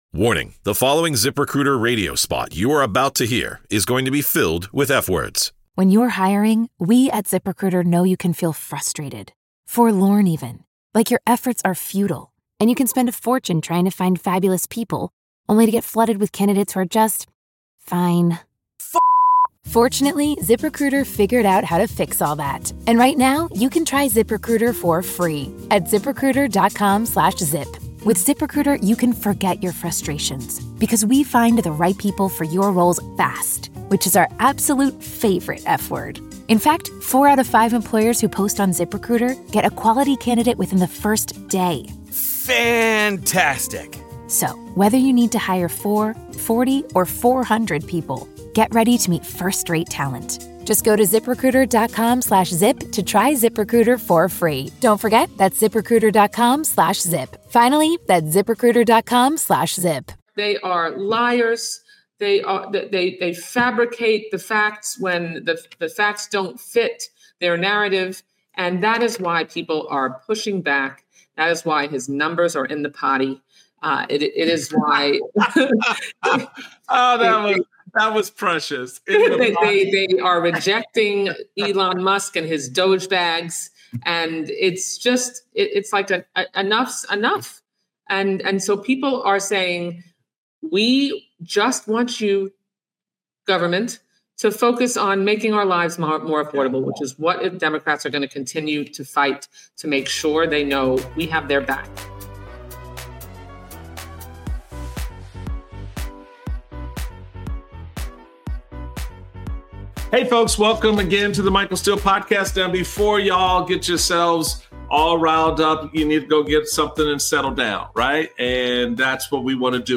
Michael Steele speaks with Florida Congresswoman Debbie Wasserman Schultz about Trump's First 100 Days of his second term. They discuss the economic impact Americans are facing, the deporations occurring without due process and what Democrats can do to expose the incompetency ...